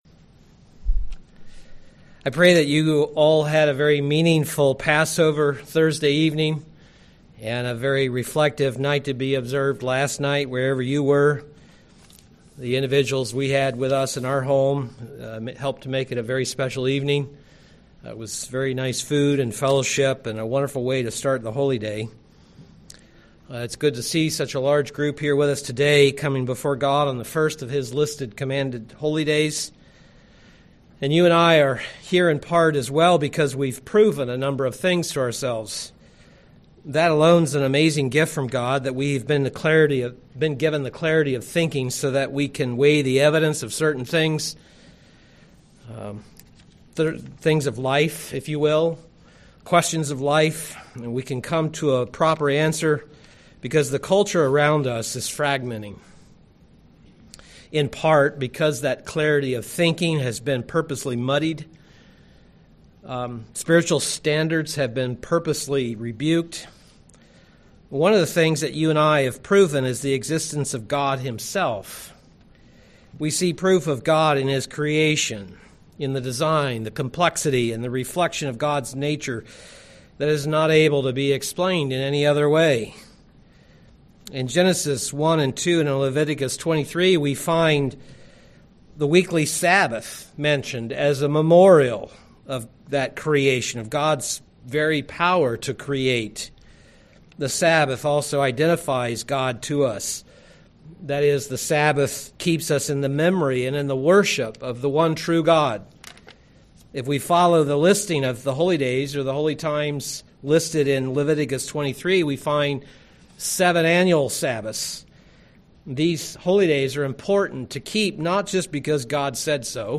Given in Milwaukee, WI
UCG Sermon Exodus Studying the bible?